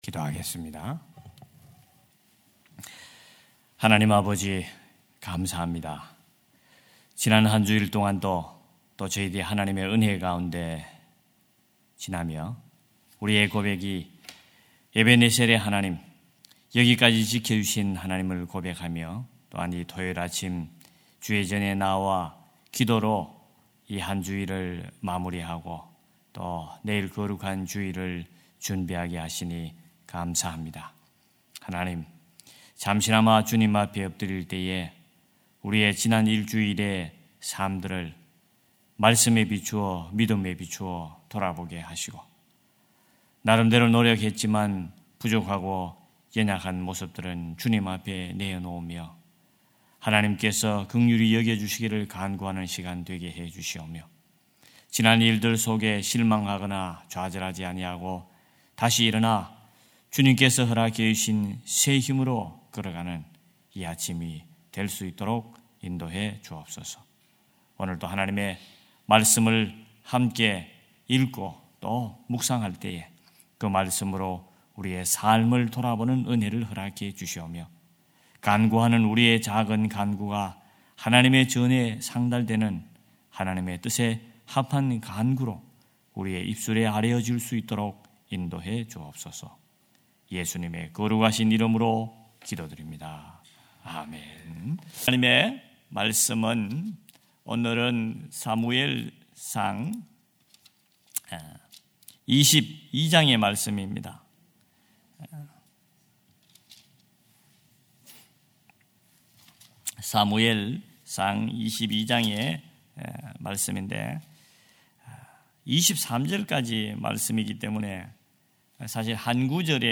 5.03.2025 새벽예배 사무엘상 22장 20절-23절